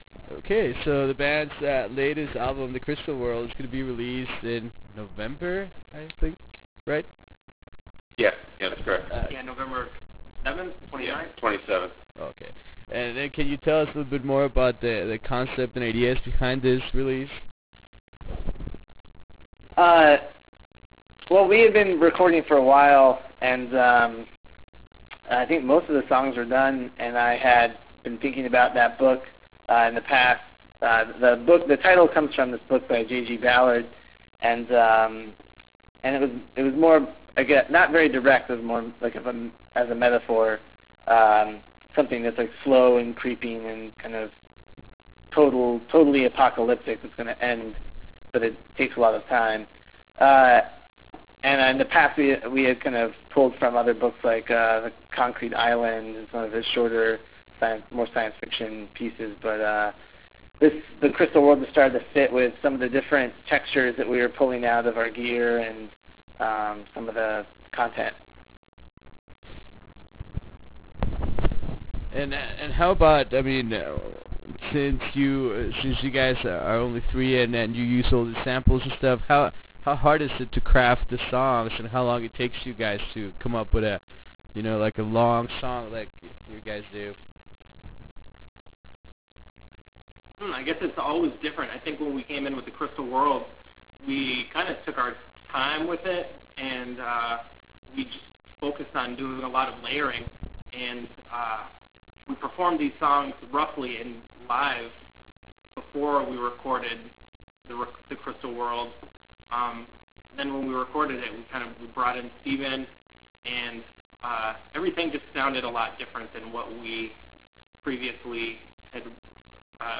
Interview with Locrian
Few weeks before the release of their next monumental album “The Crystal World”, we managed to have a conversation with all three members of Locrian. In this interview we discussed the mentality behind the recording of “The Crystal World”, how did process happened, and what did they wanted to do differently from previous releases.
Interview with Locrian.wav